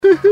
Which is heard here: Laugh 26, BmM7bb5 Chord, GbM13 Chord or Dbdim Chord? Laugh 26